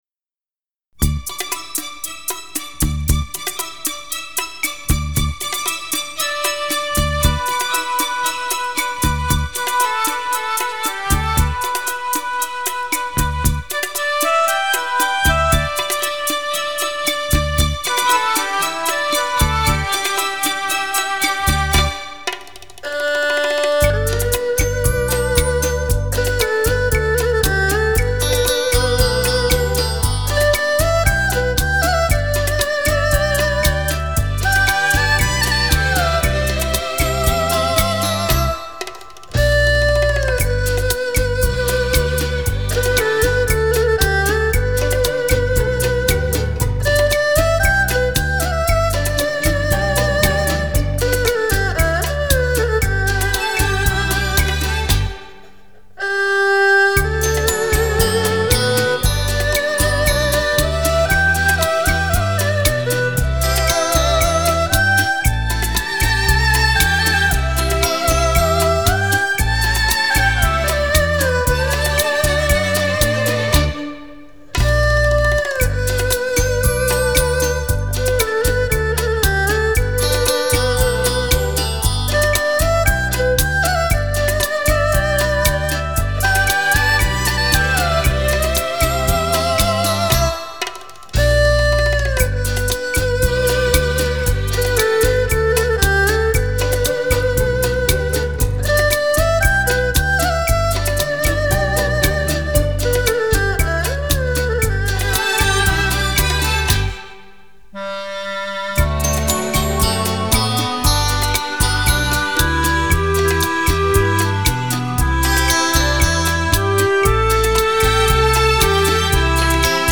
二胡/高胡
扬琴
古筝
笛子
琵琶
管子
唢呐
套鼓
低音吉他
小提琴
美妙绝伦天籁琴音
脉脉深情流动旋律
典雅、清丽的乐音将带您体会那份久远的浪漫深情